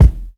INSKICK04 -L.wav